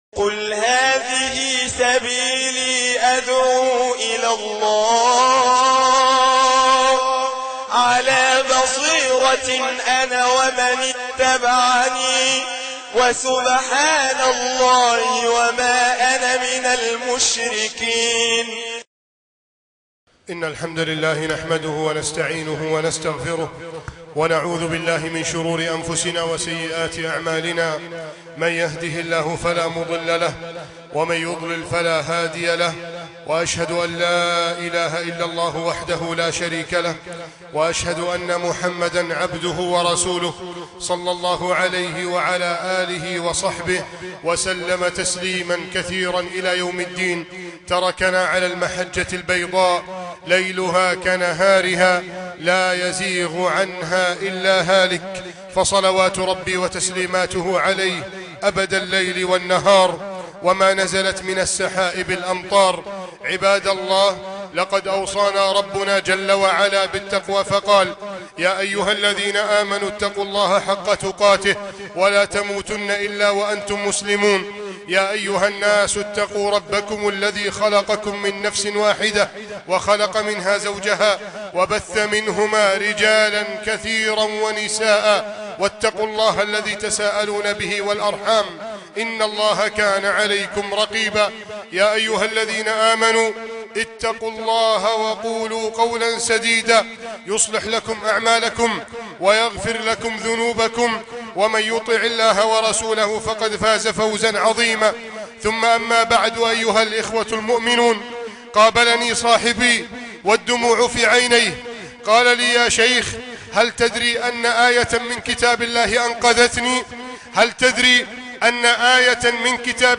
أنقذتنى آية ( خطبة الجمعة ) مسجد التابعين - بنها